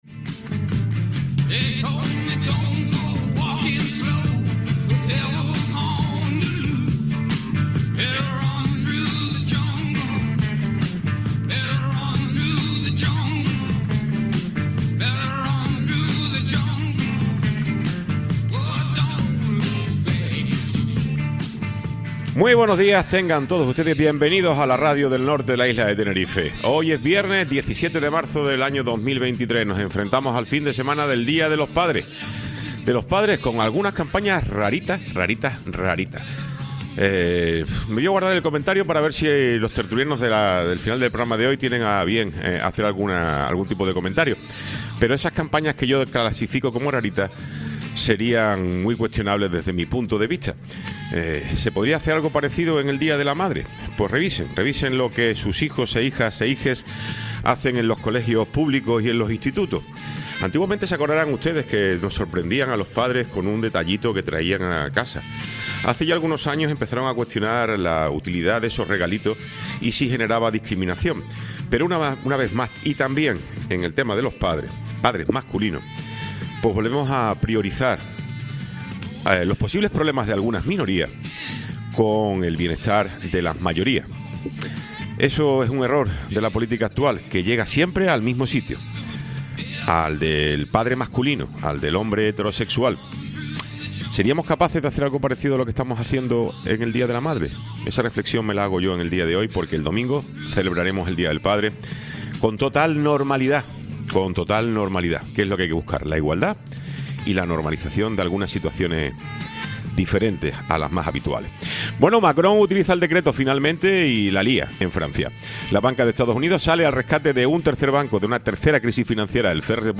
Tiempo de entrevista
Tertulia